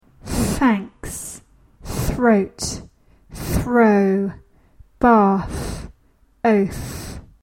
th-voiceless.mp3